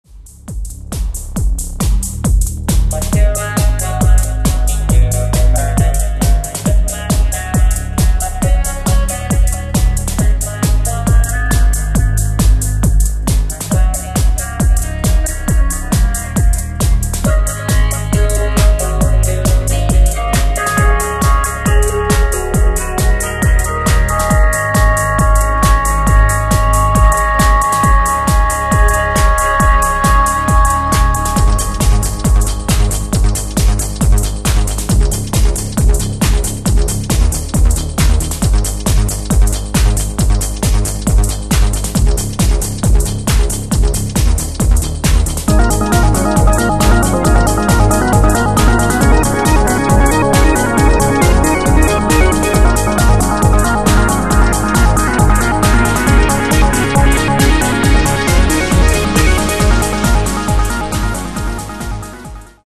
80年代エレクトリックミュージックを意識し、テクノポップの実践を試みたオリジナル曲集。
DEMO 752455 bytes / 01:02 / bpm136
MPEG 1.0 layer 3 / 96kbit / stereo